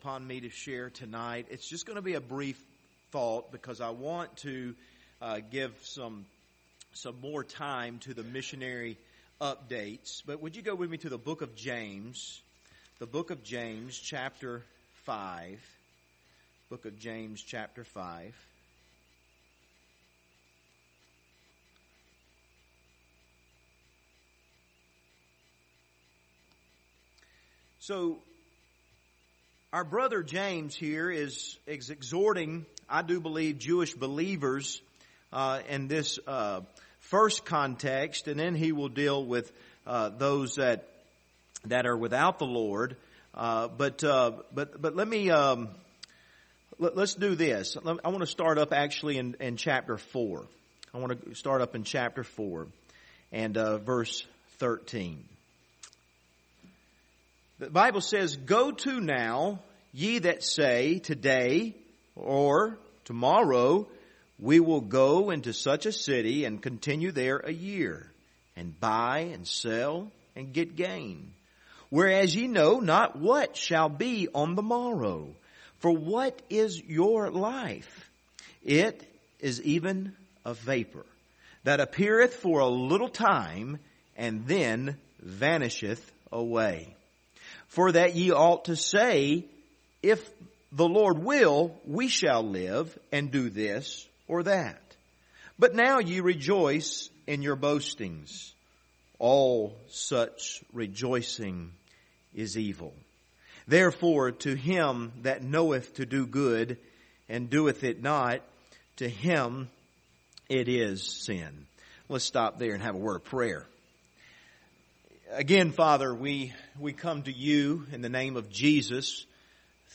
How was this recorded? Passage: James 4:13-17 Service Type: Wednesday Evening Topics